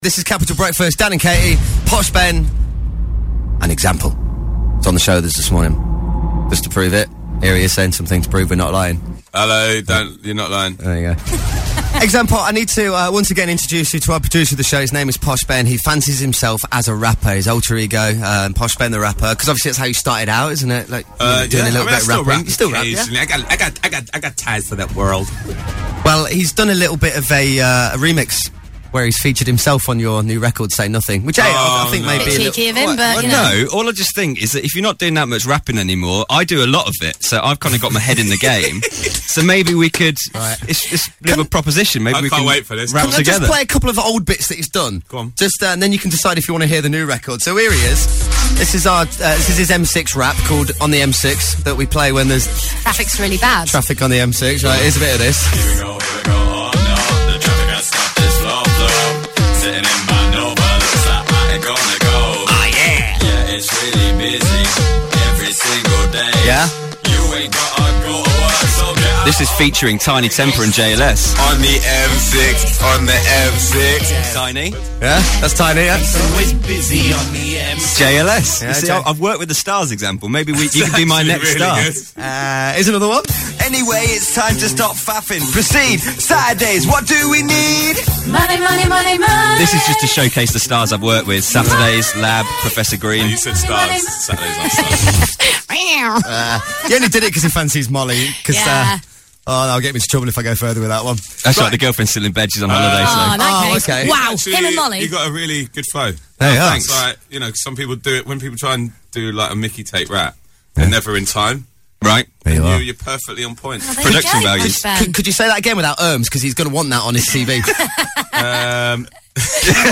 Example was a guest on the Capital Breakfast Show, so I decided to showcase my musical talents to him...